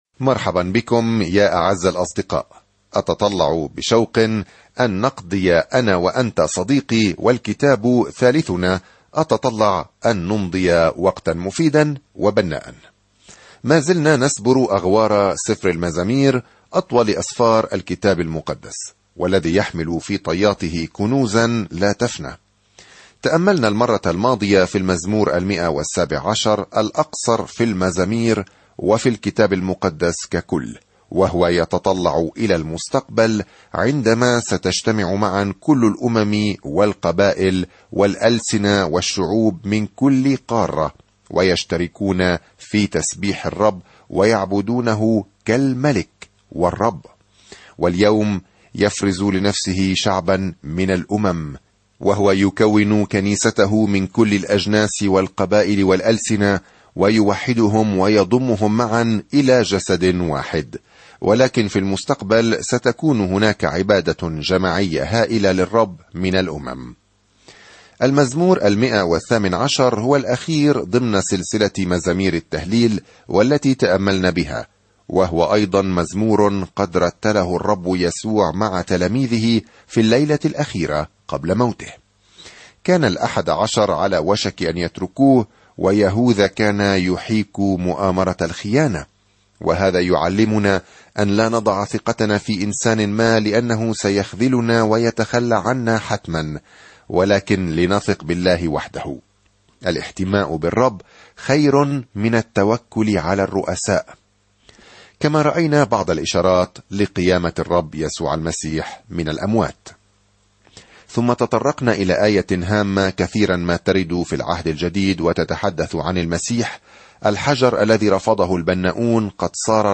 الكلمة اَلْمَزَامِيرُ 119 يوم 46 ابدأ هذه الخطة يوم 48 عن هذه الخطة تعطينا المزامير أفكارًا ومشاعر مجموعة من التجارب مع الله؛ من المحتمل أن كل واحدة تم ضبطها في الأصل على الموسيقى. سافر يوميًا عبر المزامير وأنت تستمع إلى الدراسة الصوتية وتقرأ آيات مختارة من كلمة الله.